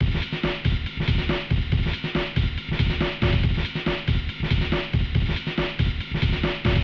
NoiseEdited Amen Break
Horror Loop